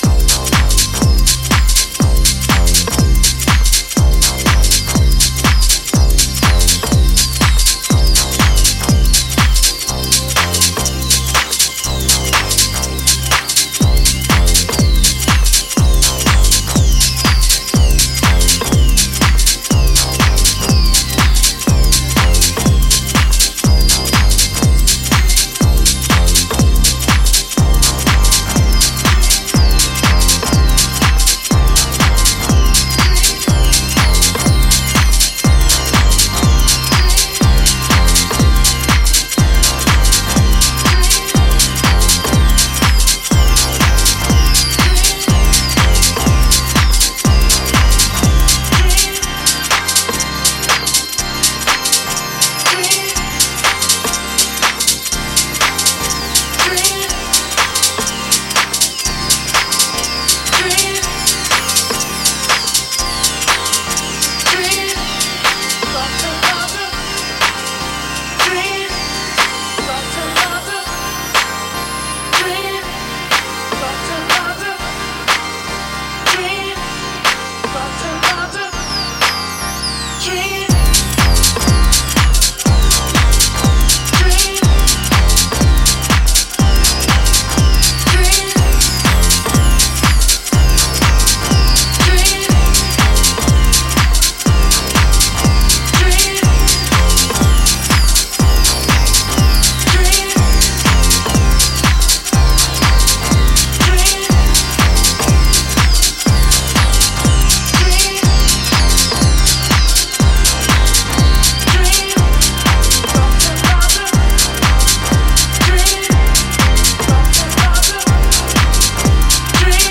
新旧のハウス・ファンに向けた隙のない一枚です！